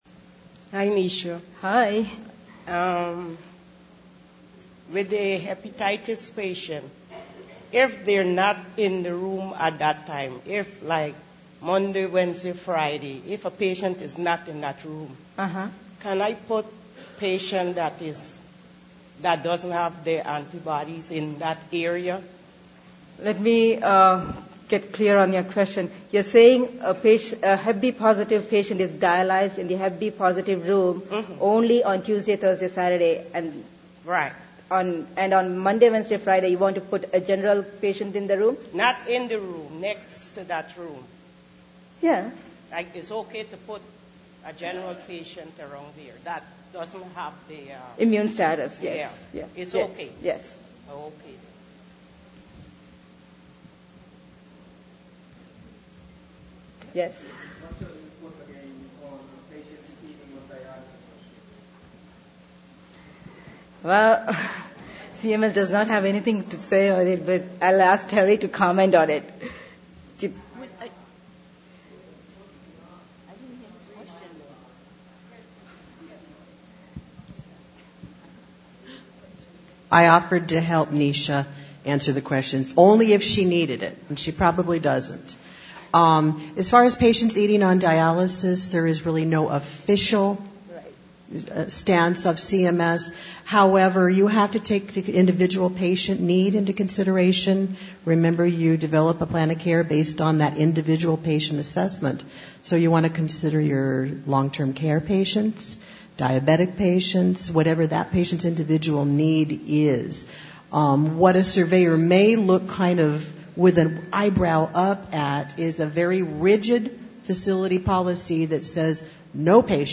Questions And Answers